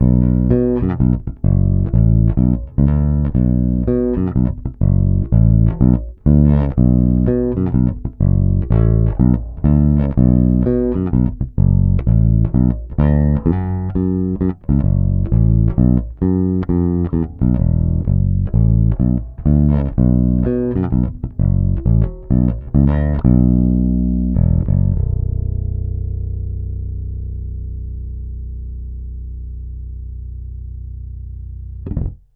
Nahrávky jsou bez kompresoru, linka do zvukovky.
Trochu je slyšet brum, ale to mi dělá dnes elektrika doma.